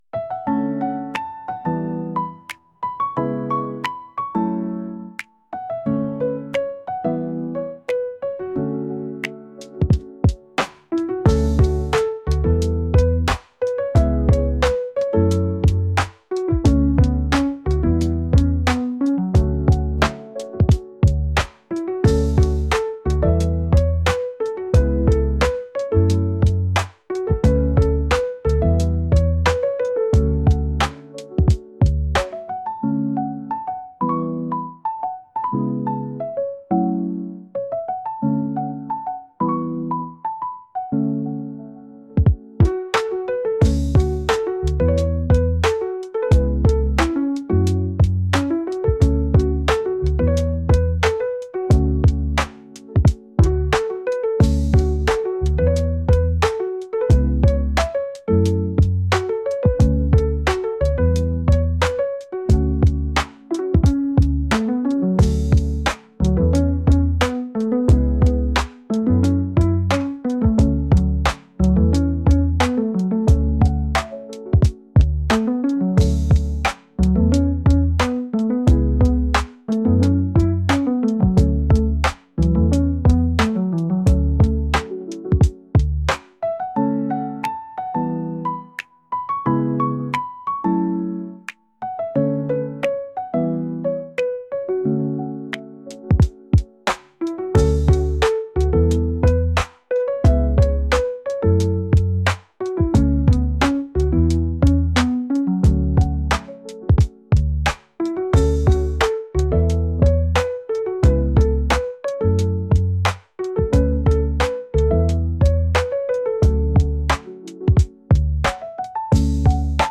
激しくもないゆったりした音楽です。